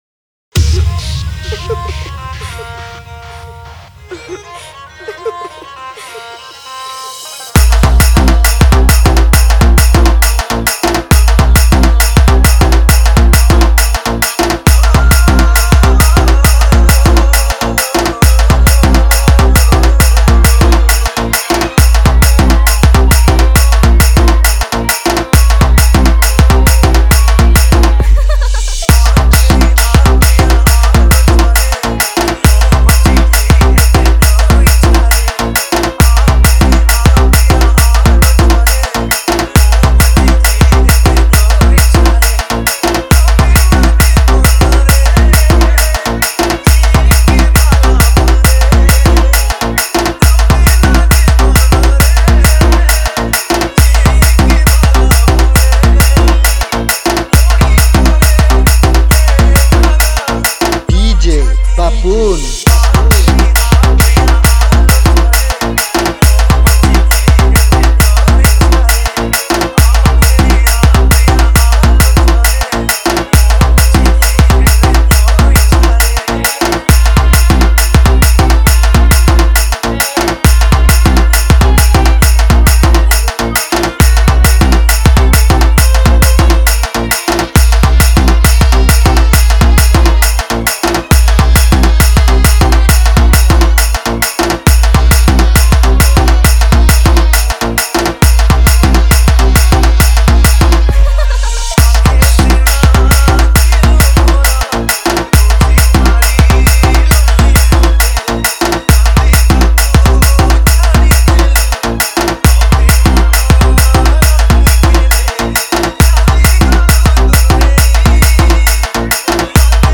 Category:  New Odia Dj Song 2021